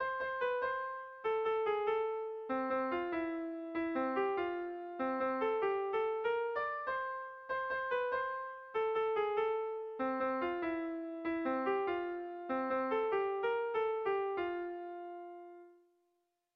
Ta gu gera ta gu gera - Bertso melodies - BDB.
Kontakizunezkoa
Sei puntuko berdina, 8 silabaz
ABDABD